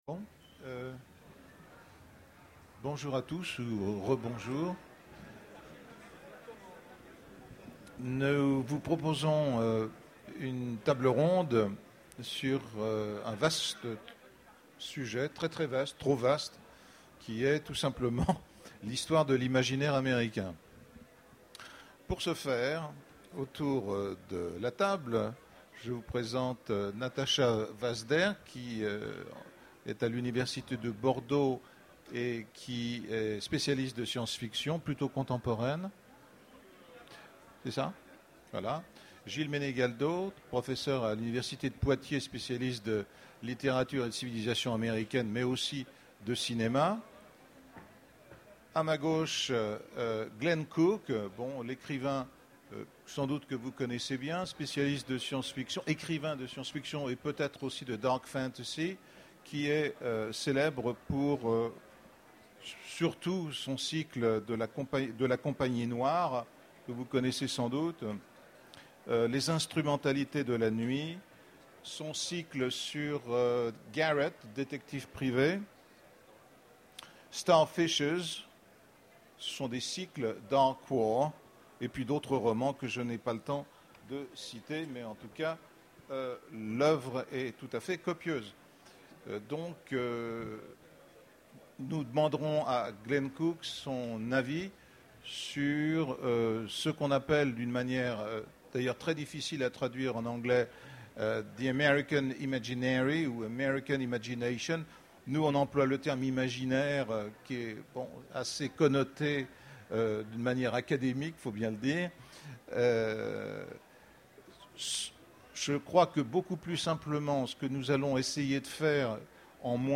Utopiales 2011 : Conférence Histoire de l'Imaginaire Américain